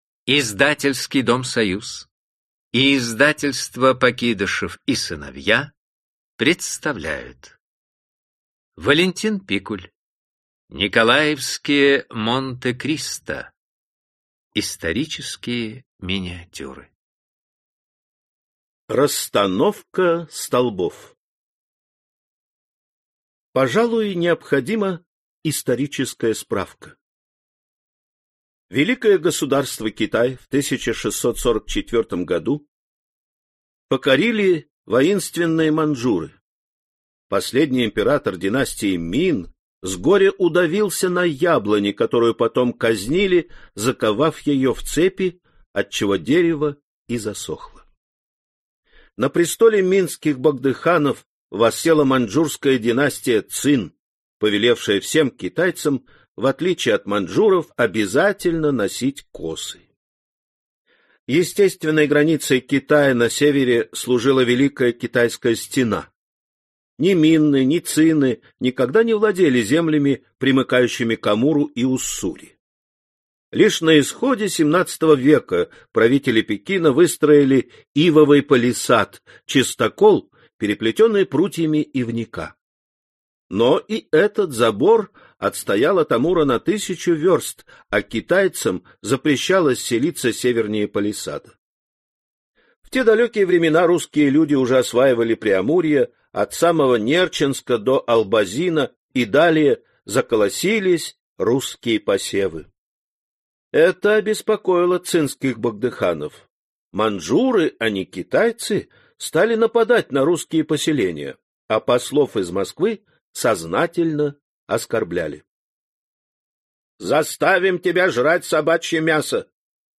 Аудиокнига Николаевские Монте-Кристо | Библиотека аудиокниг
Aудиокнига Николаевские Монте-Кристо Автор Валентин Пикуль Читает аудиокнигу Александр Клюквин.